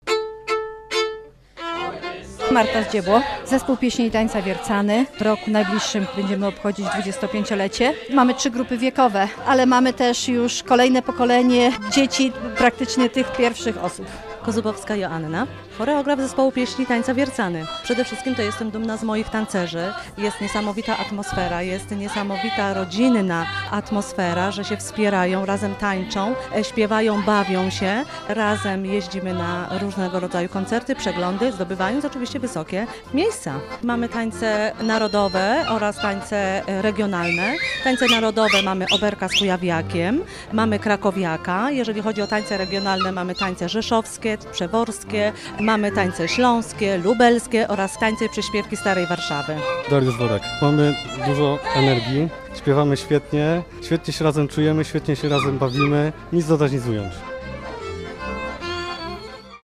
Uczestnikom towarzyszy muzyka i taniec w wykonaniu Zespołu Tańca Ludowego „Sanok”, Regionalnego Zespołu Pieśni i Tańca „Markowianie” oraz Zespołu Pieśni i Tańca „Wiercany”. Ci ostatni mówią, że zespół łączy tradycje i pokolenia: